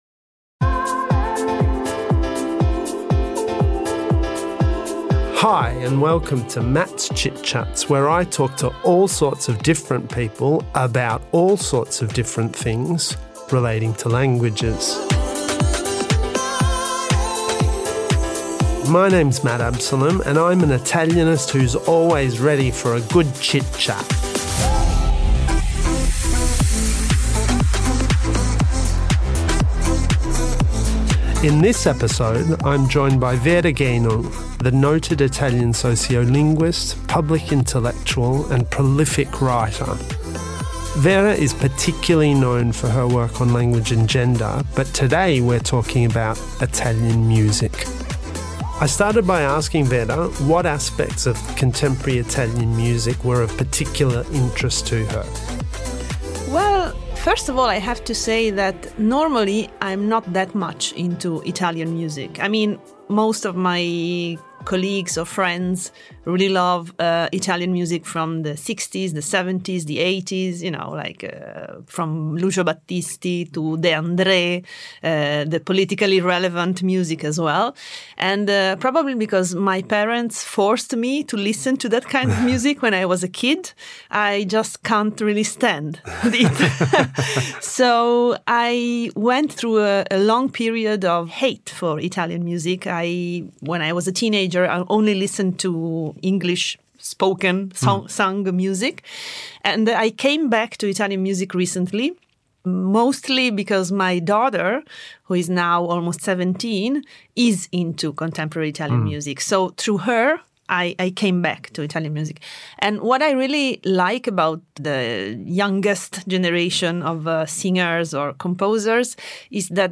A Conversation with Vera Gheno 37:12